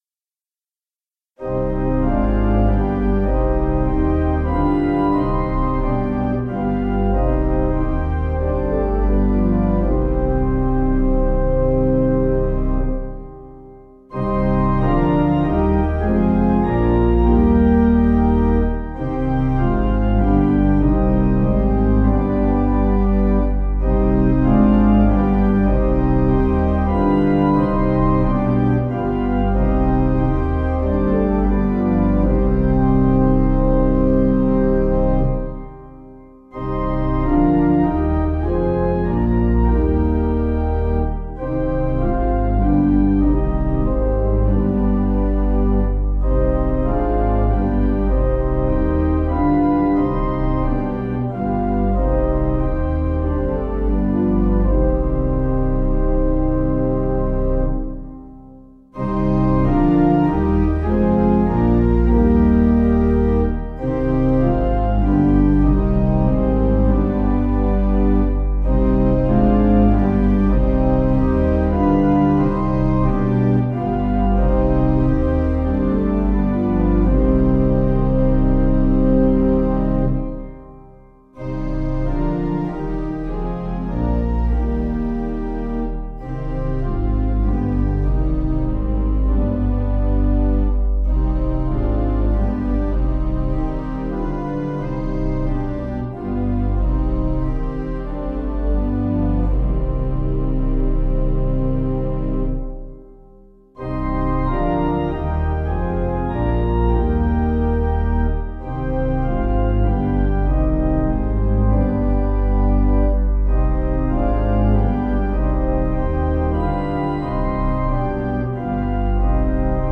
Information about the hymn tune FONS AMORIS (Wiseman).
Key: c minor